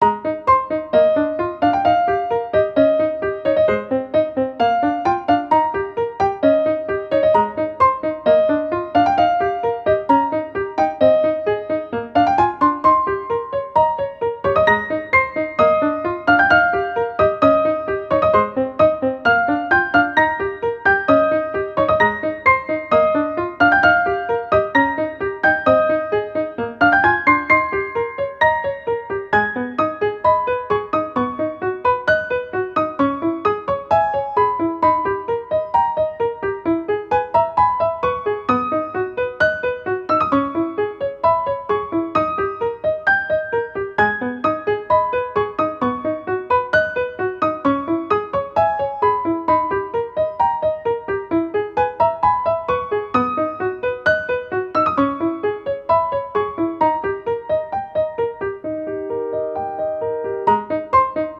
ogg(L) - 切ない スタッカート クール
弾む曲調ながらどこか心に沁みる不思議ピアノ。